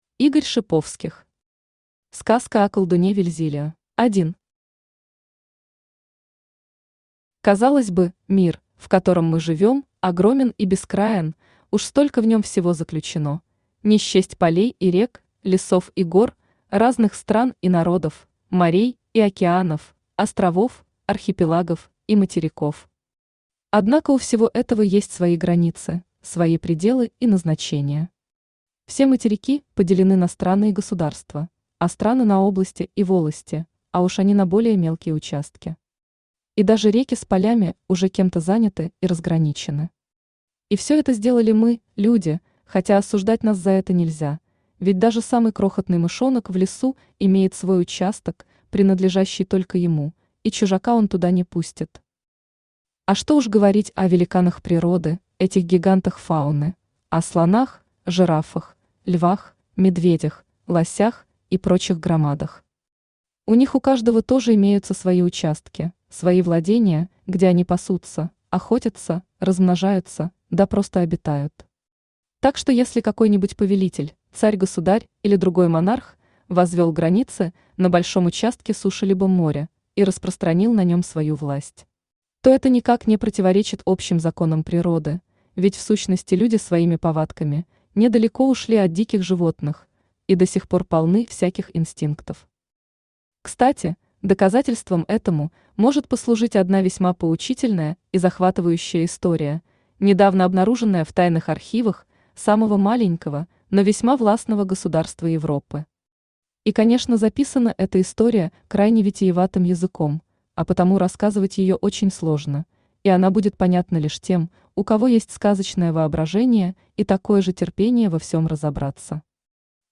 Аудиокнига Сказка о колдуне Вельзилио | Библиотека аудиокниг
Aудиокнига Сказка о колдуне Вельзилио Автор Игорь Дасиевич Шиповских Читает аудиокнигу Авточтец ЛитРес.